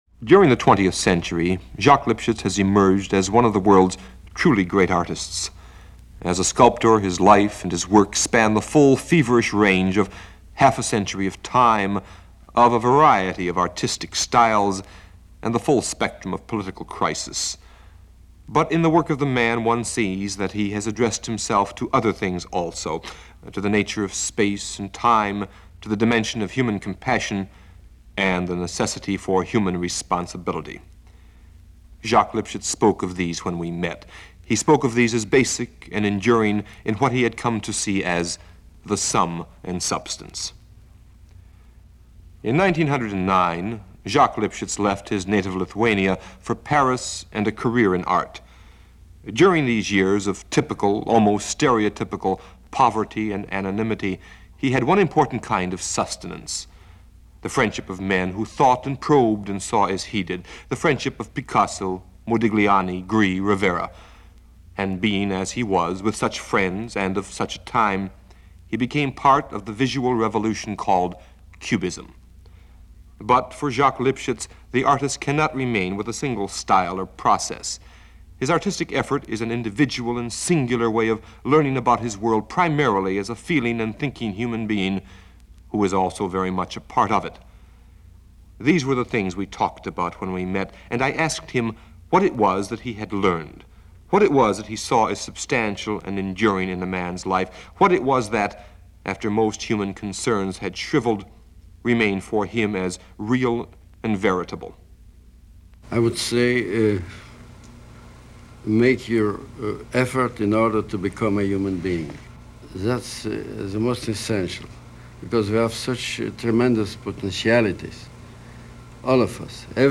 An interview this week with legendary sculptor/painter Jacques Lipchitz.
Jacques-LIpchitz-Intervie-1963.mp3